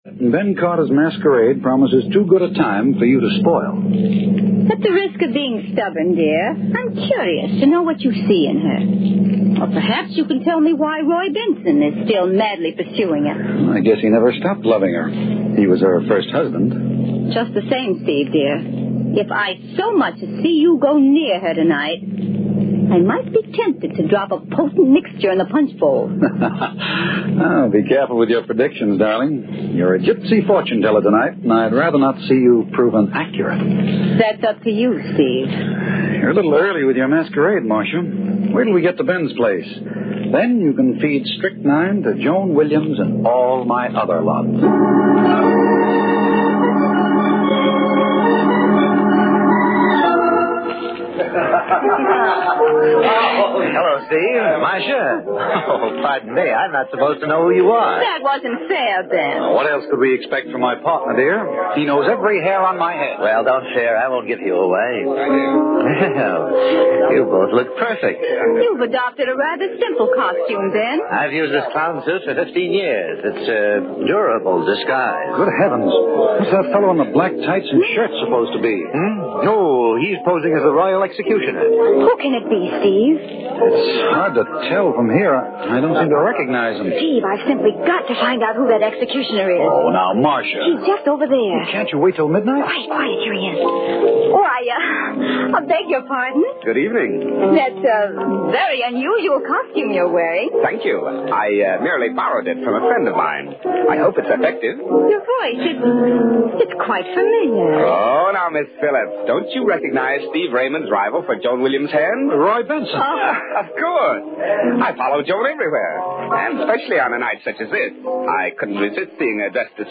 Category: Radio   Right: Personal
Tags: Radio Horror Mystery Radio Show The Haunting Hour